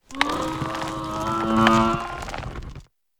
Grito de Klawf.ogg
Grito_de_Klawf.ogg